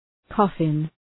Προφορά
{‘kɔ:fın}